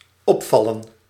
Ääntäminen
France: IPA: /fʁa.ˈpe/